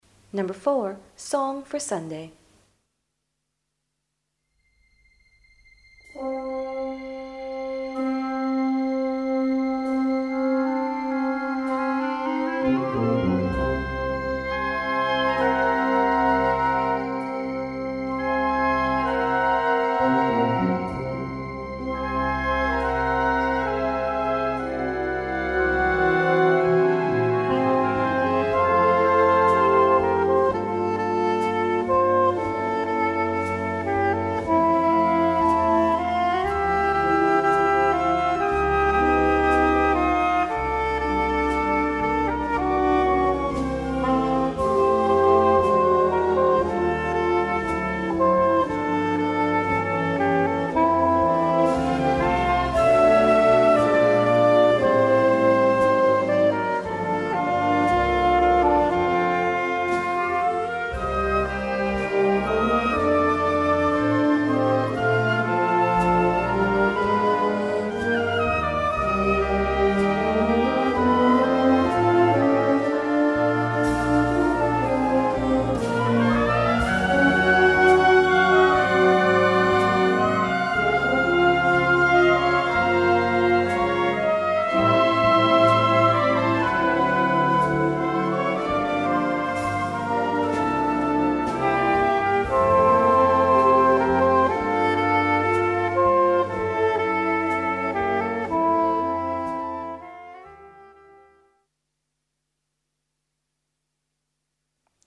Wind Orchestra Grade 3-5